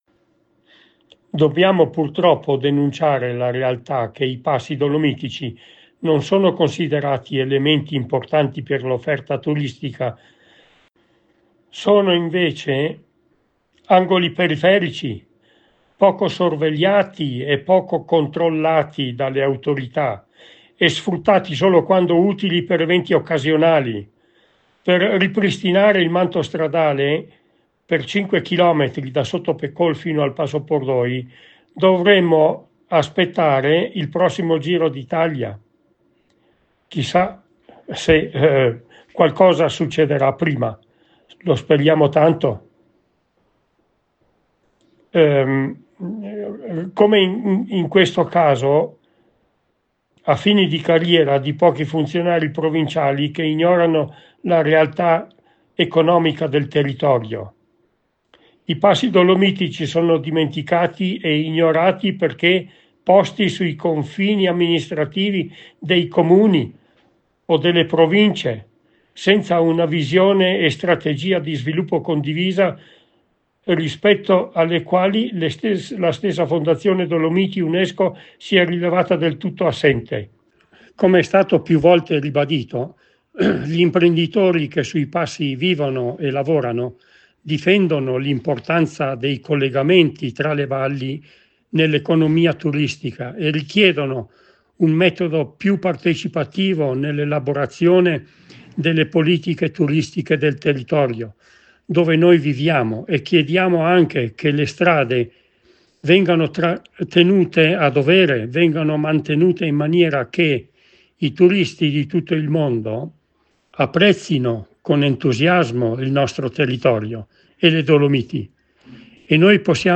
LO SFOGO ALLA RADIO